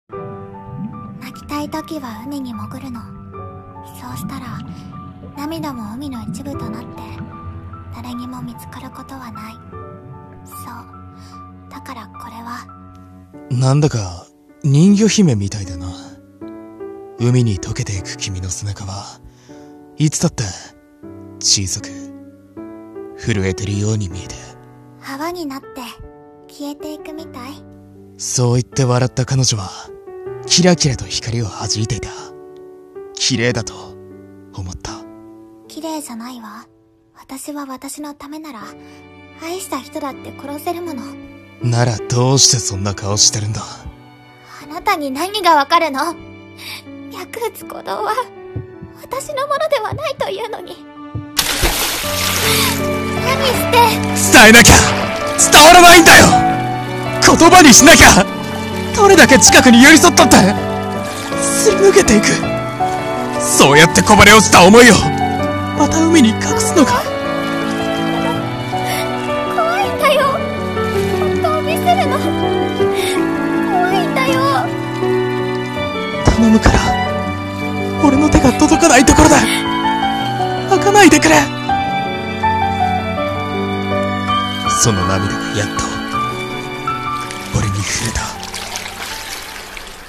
【二人声劇】人魚姫のメーデー